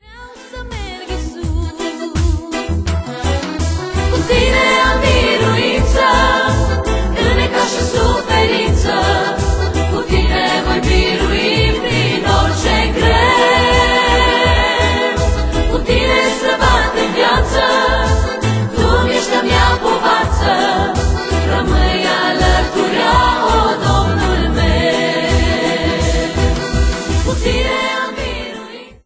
inviorand prin dinamica liniilor melodice